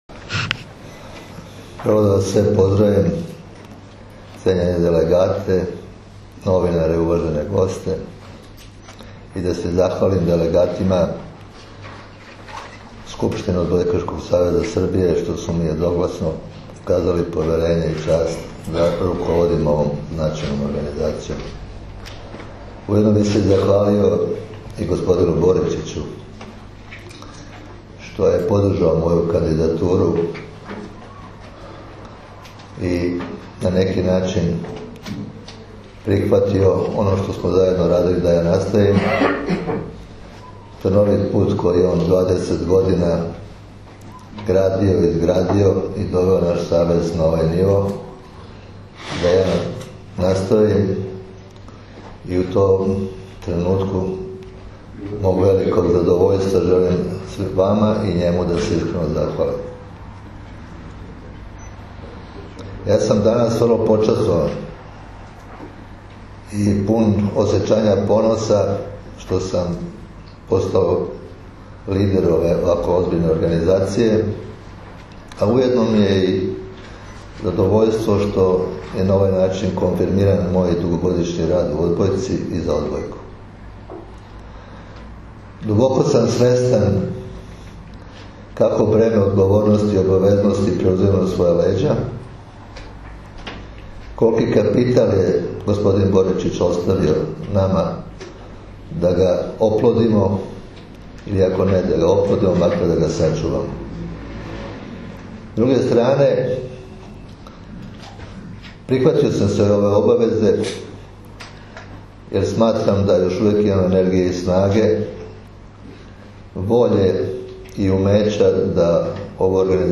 ODBOJKAŠKI SAVEZ SRBIJE – IZBORNA SKUPŠTINA
Izborna Skupština Odbojkaškog saveza Srbije održana je danas u beogradskom hotelu „M”, a pravo glasa imalo je 47 delegata.